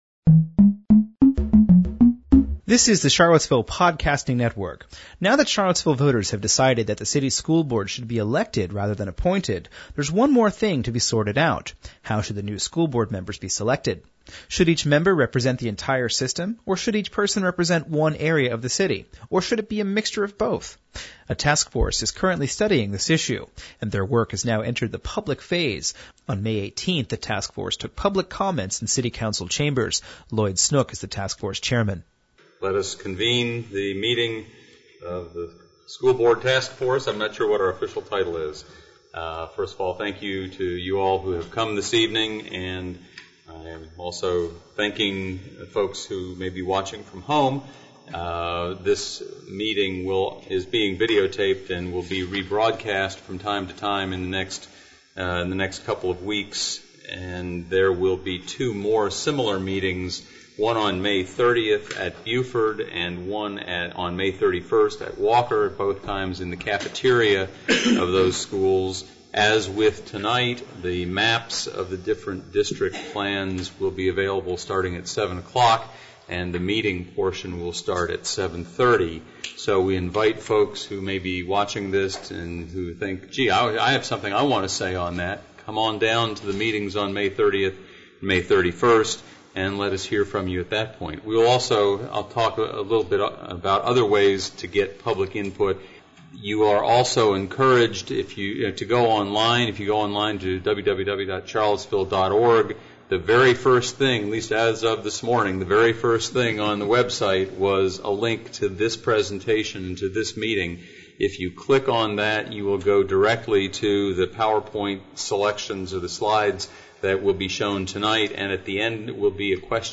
About three hundred people gathered this morning under a bright April sun on the east end of the Charlottesville downtown mall to be among the first to watch someone write on the new community chalkboard. The 42-foot-long slate wall is a monument to the First Amendment built by the Thomas Jefferson Center for Free Expression. Area celebrities were in attendance, and short remarks were made by George Garrett, John Grisham and Boyd Tinsley. We present the entire event in this downloadable recording, along with reactions from people who were there.